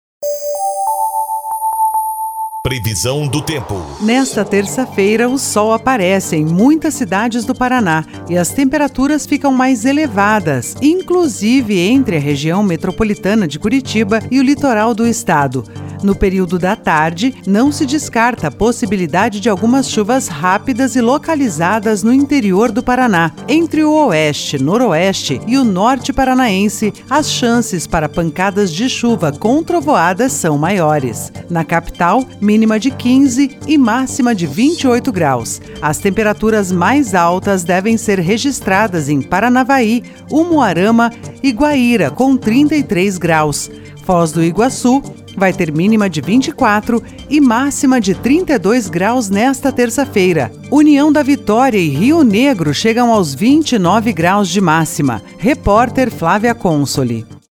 Previsão do Tempo 21/11/23